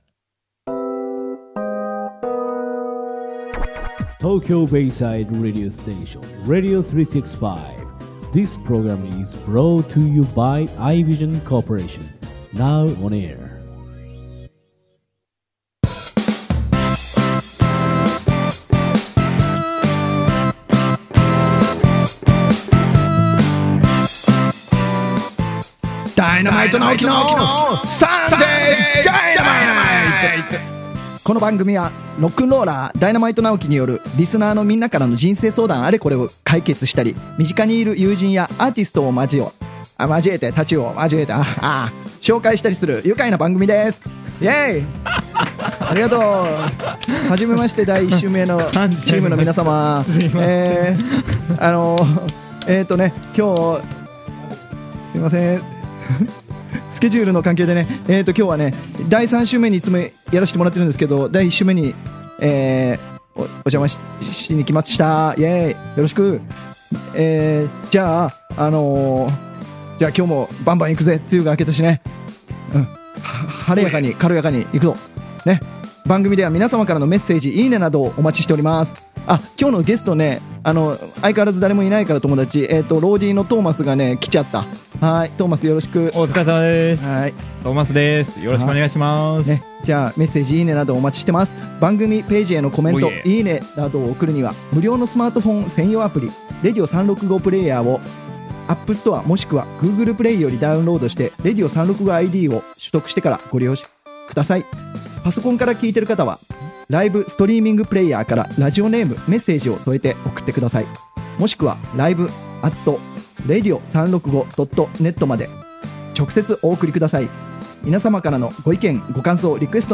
【この音源は生放送のアーカイブ音源となります。】